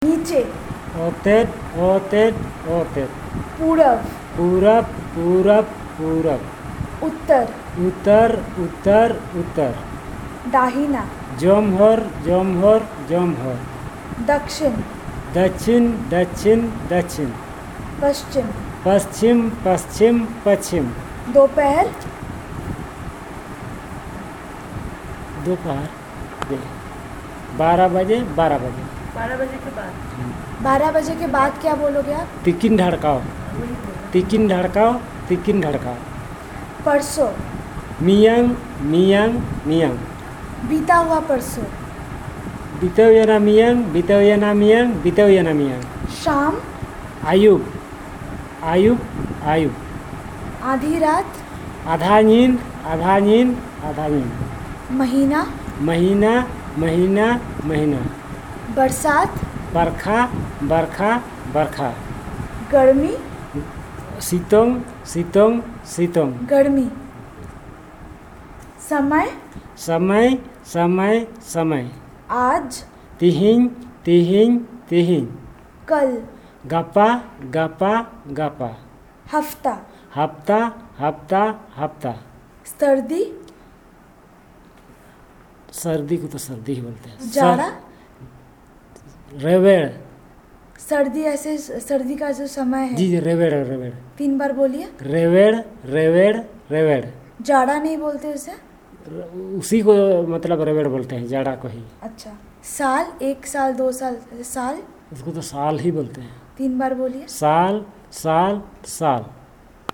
NotesThis is an elicitation of words about time, directions and seasons using Hindi as the language of input from the researcher's side, which the informant then translates to the language of interest